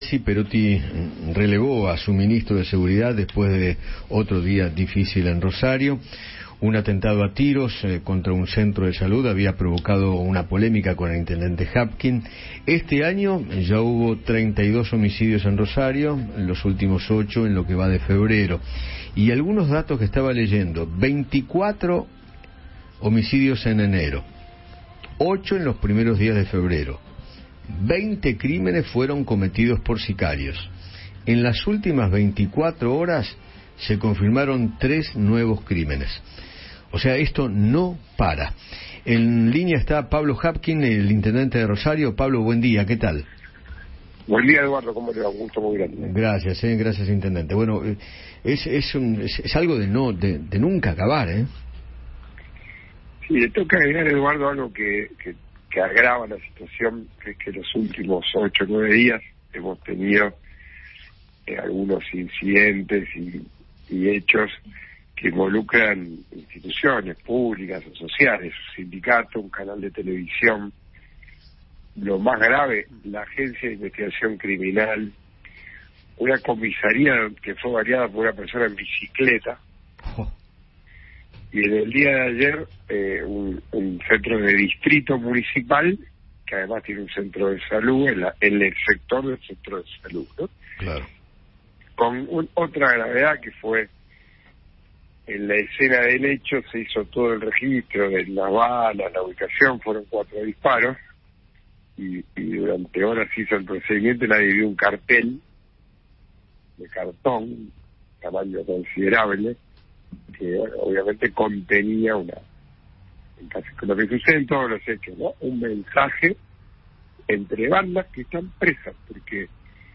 Pablo Javkin, intendente de Rosario, conversó con Eduardo Feinmann sobre la remoción de Rubén Rimoldi de su cargo como ministro de Seguridad de Santa Fe y analizó la situación de su ciudad.